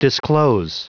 Prononciation du mot disclose en anglais (fichier audio)
Prononciation du mot : disclose